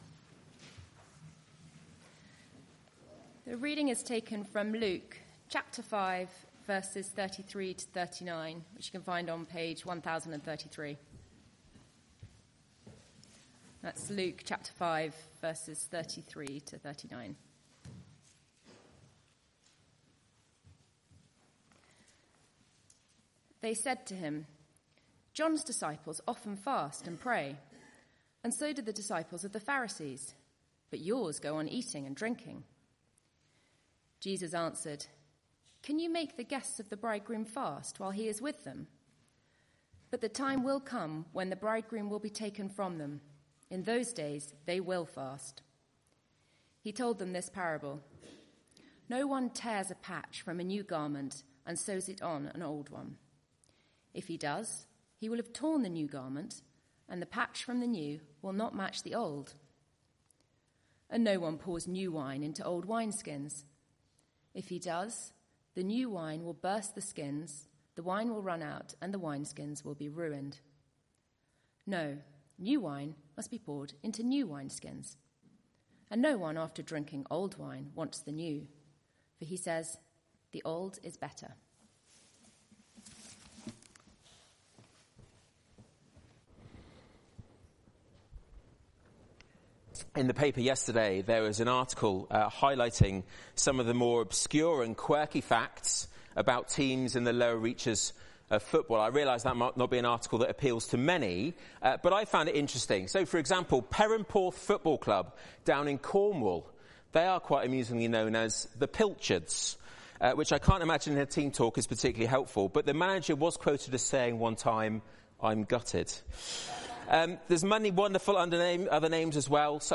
Media for Arborfield Morning Service
Sermon